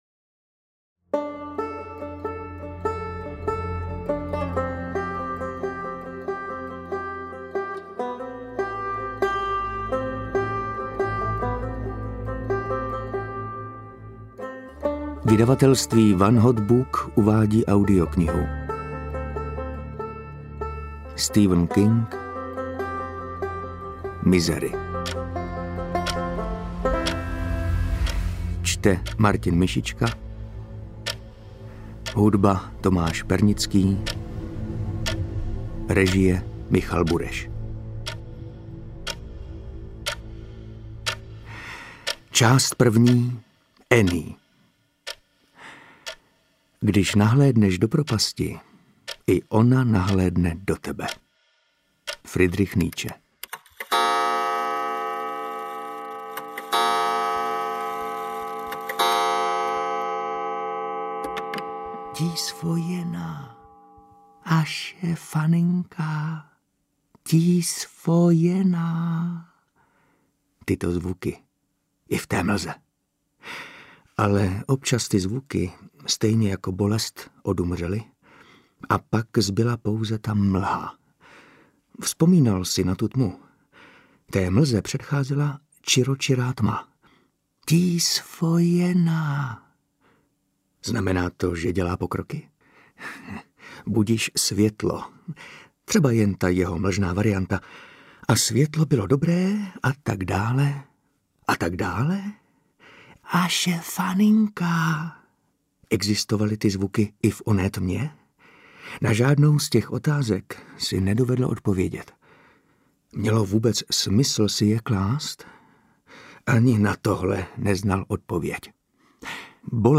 Interpret:  Martin Myšička